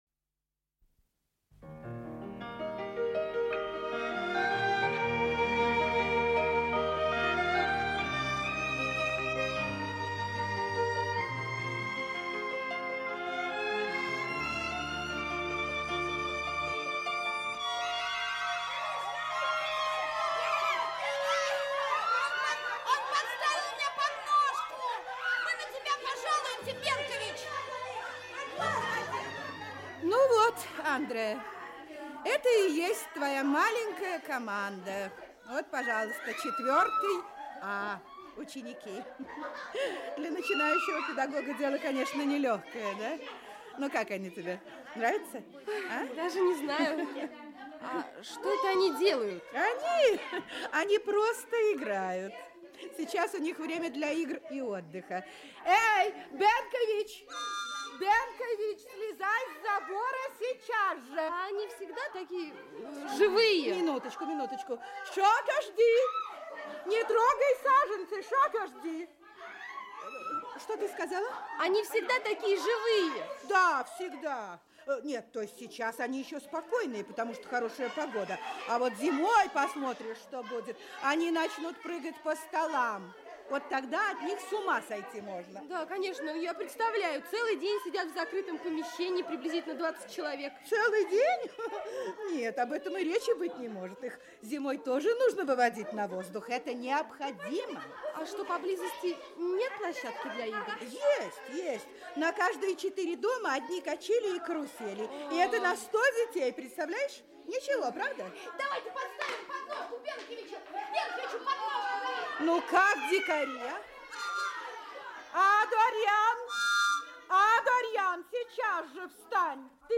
Аудиокнига Палатка | Библиотека аудиокниг
Aудиокнига Палатка Автор Анико Четени Читает аудиокнигу Актерский коллектив.